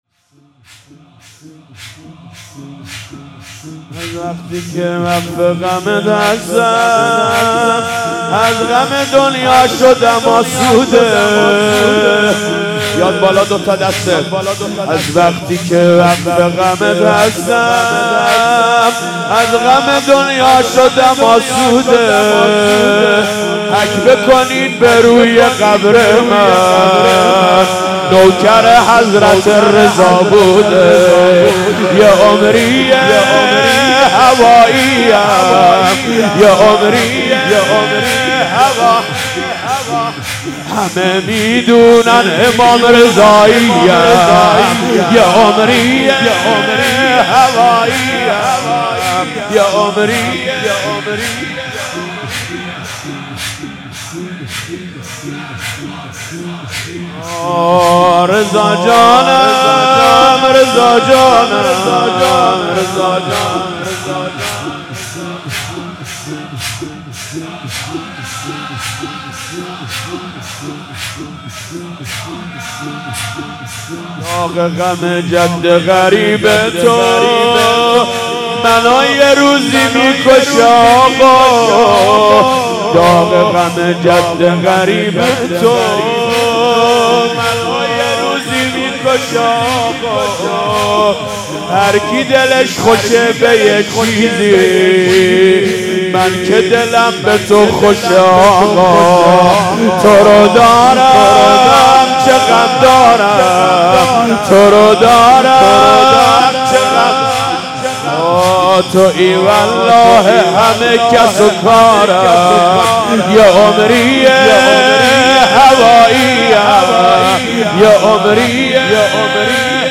شهادت حضرت امام رضا(ع)/17آبان97